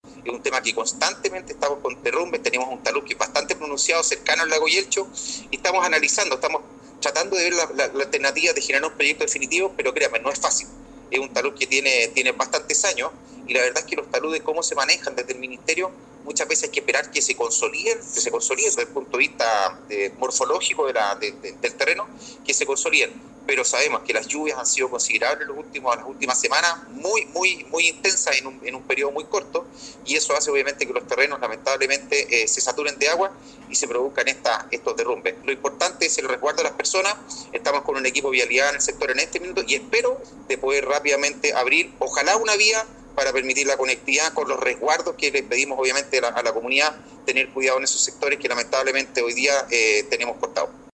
También el factor de intensas precipitaciones en los últimos días ha generado que el terreno se mantenga muy alterado, dijo el representante de Obras Públicas.